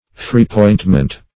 Search Result for " foreappointment" : The Collaborative International Dictionary of English v.0.48: Foreappointment \Fore`ap*point"ment\, n. Previous appointment; preordinantion.